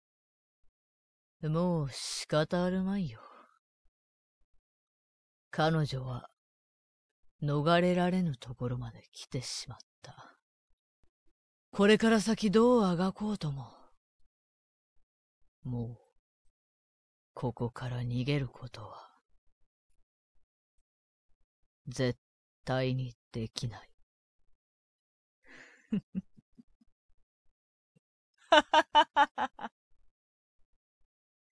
● サンプルボイス ●
↓お試し同然の無理無理とか、音質も、ノイズ処理などが適当なのも混じってます。
男04【青年・やや低】
【１】やや低。悪役風